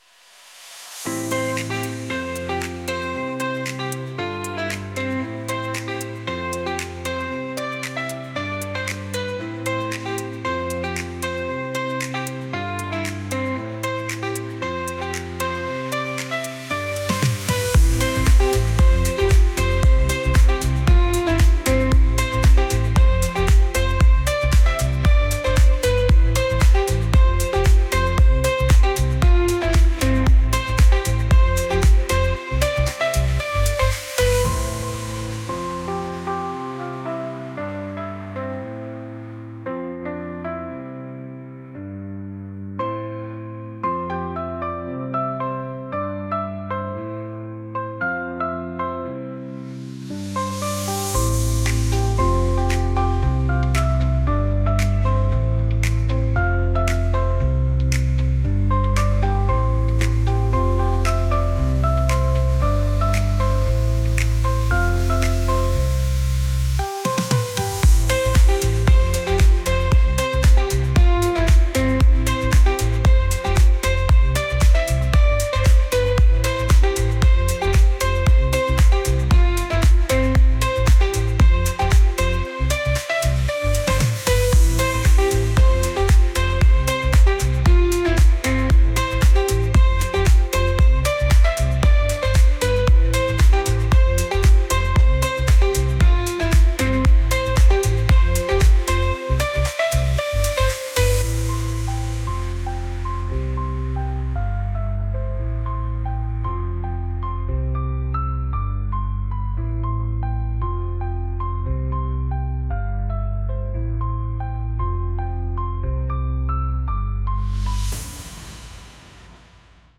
pop | soul & rnb